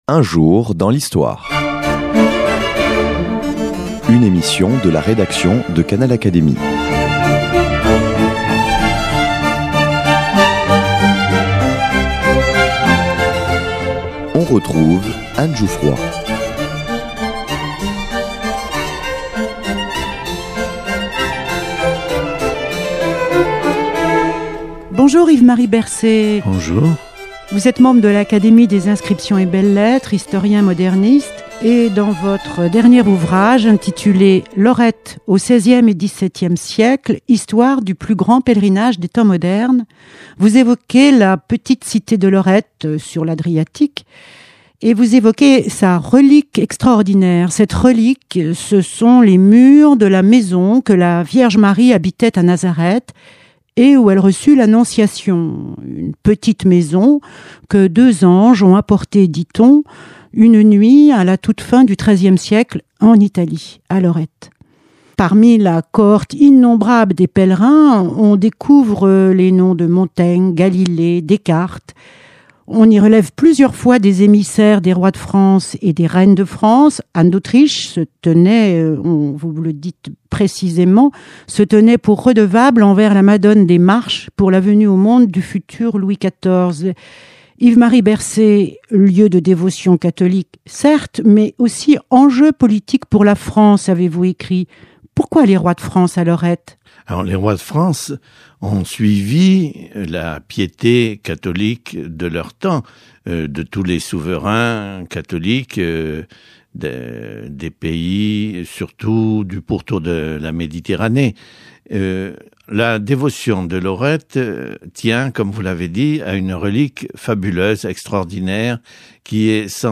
Yves-Marie Bercé, membre de l’Académie des inscriptions et belles lettres, présente son dernier ouvrage.